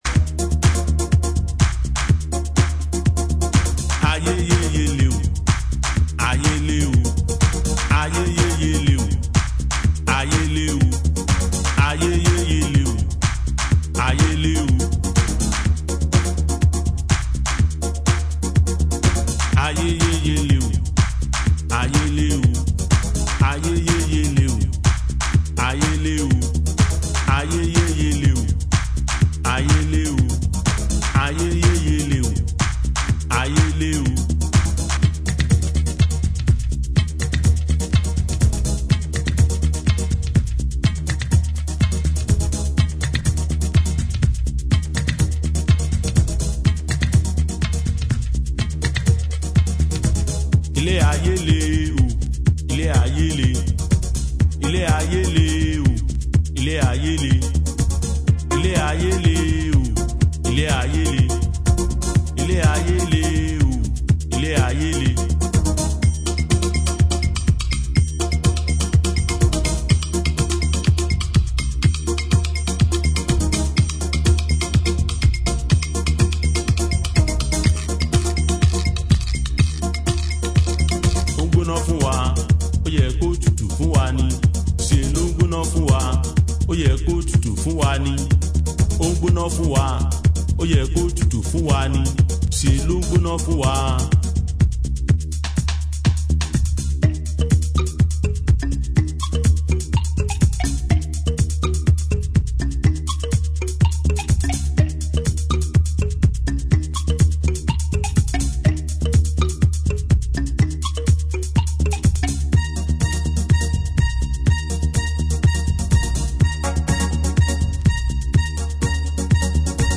ジャンル(スタイル) CLASSIC HOUSE / DEEP HOUSE